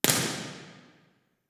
The hall was empty during the sweep recording session. The T30 is just over 1.5 sec average in the mid frequency decade. The sound was located on stage, and the sweeps were recorded at Row 2, the main horizontal aisle, and the back row on the main floor.
Microphone: Sennheiser Ambeo
Main Hall Example (Omni):